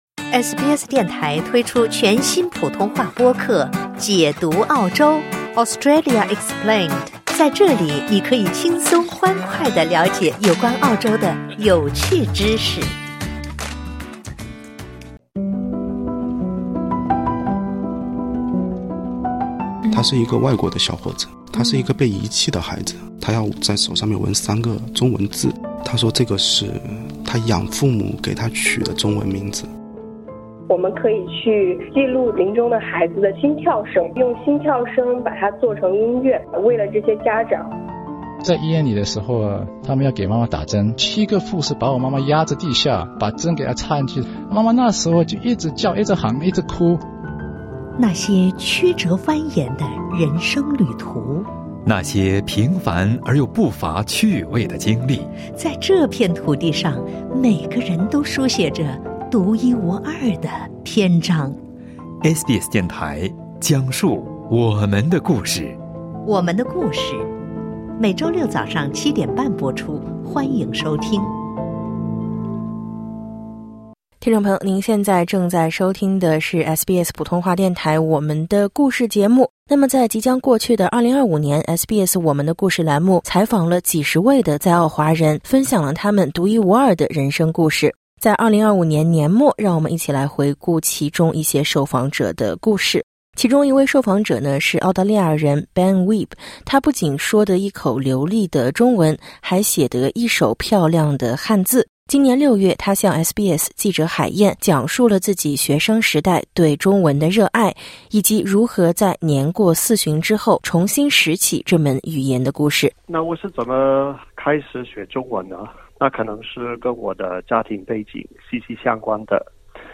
在即将过去的2025年，SBS《我们的故事》栏目邀请到数十人分享他们独一无二的人生故事。在2025年年末，让我们一起来回顾其中一些受访者的故事。